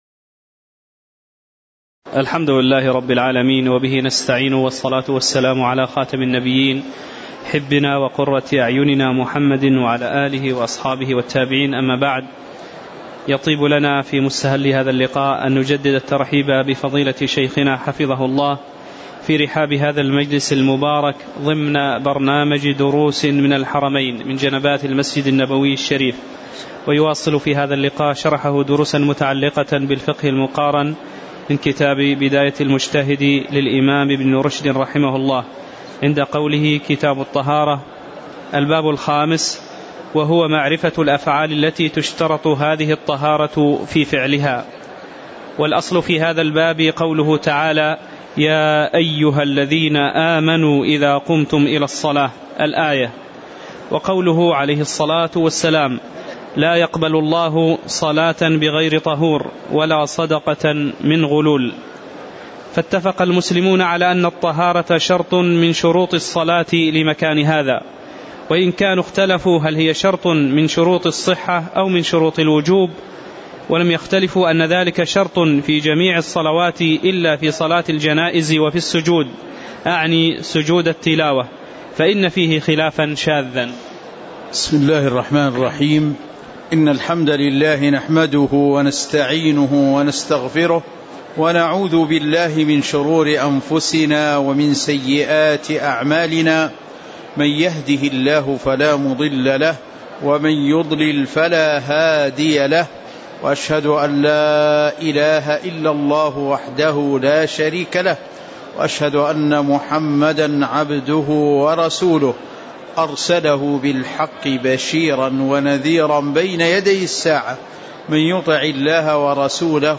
تاريخ النشر ٤ ربيع الأول ١٤٤٠ هـ المكان: المسجد النبوي الشيخ